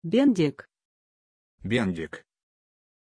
Prononciation de Bendik
pronunciation-bendik-ru.mp3